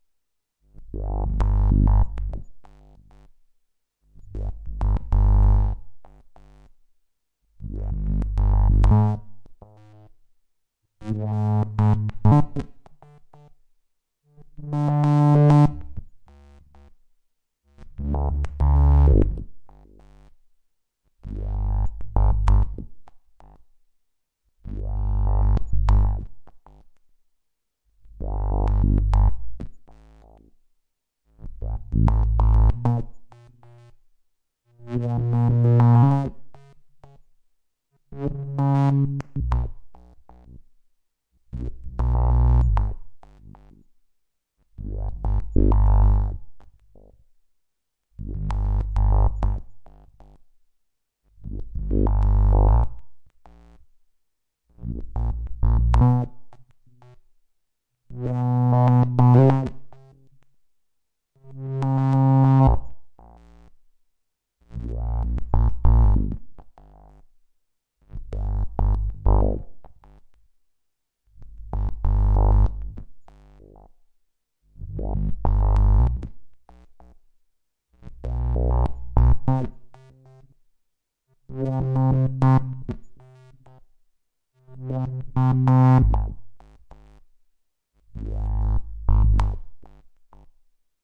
模块化说话的低音对你喜欢的部分进行采样
描述：低音模块合成器的变化。原始的波浪是一个罪恶的波...我认为我最喜欢的是六号。十号也很时髦。
Tag: 模拟 合成器 循环 合成器 模块化 贝司